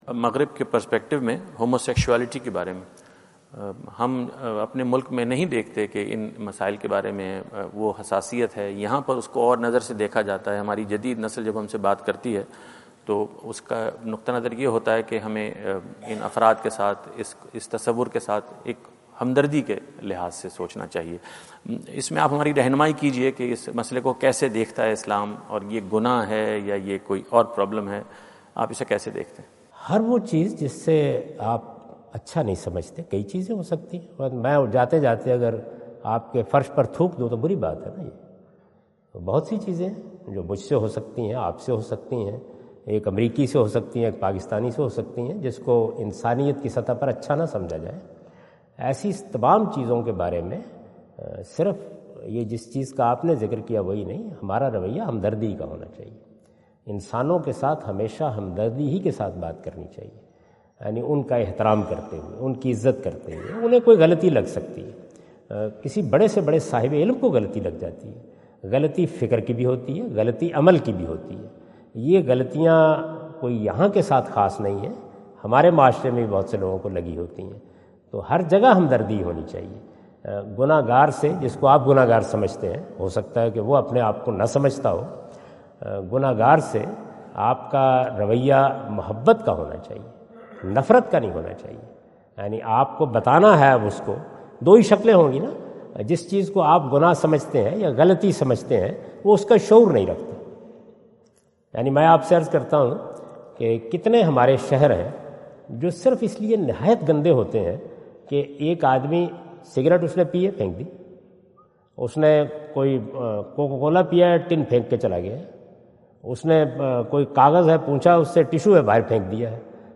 Javed Ahmad Ghamidi answer the question about "Homosexuality, Society and Islam?" During his US visit at Wentz Concert Hall, Chicago on September 23,2017.